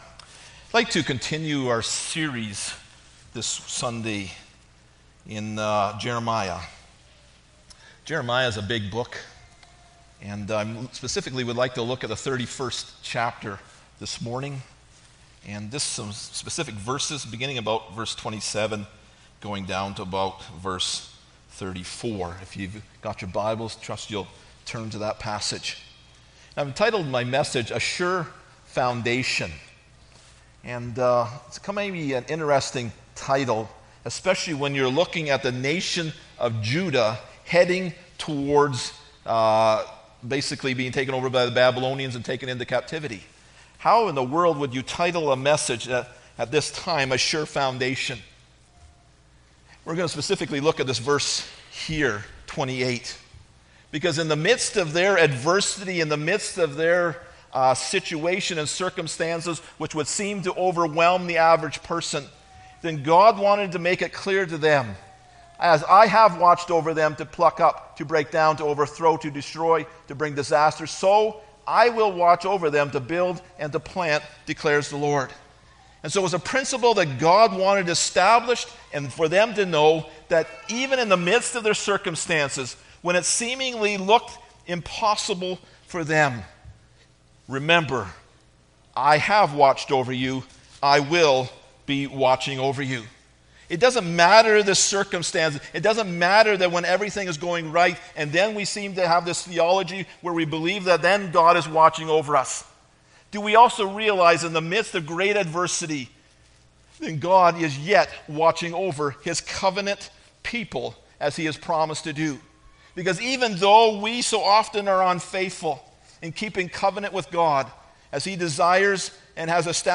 Jeremiah 31:28-33 Service Type: Sunday Morning Bible Text